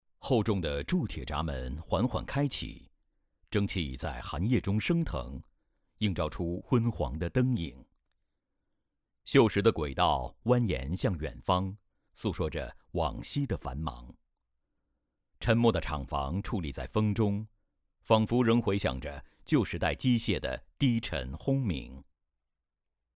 성우샘플
차분/편안